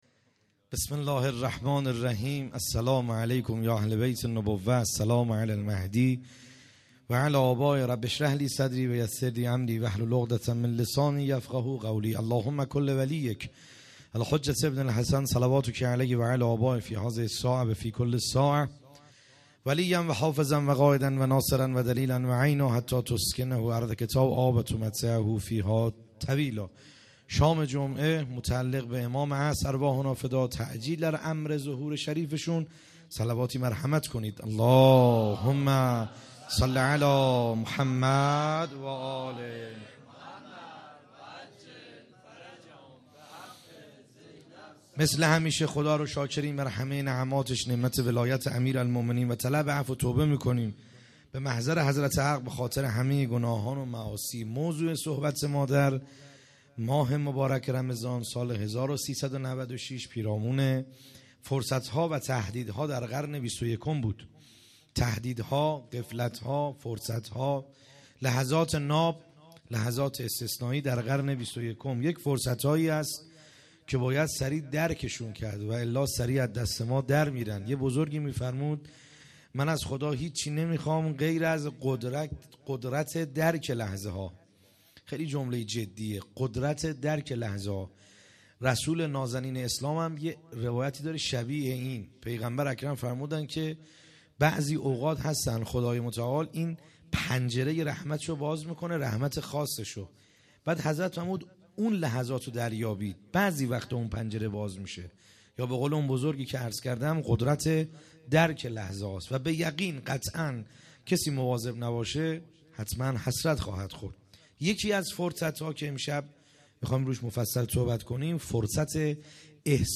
خیمه گاه - بیرق معظم محبین حضرت صاحب الزمان(عج) - سخنرانی | شب هشتم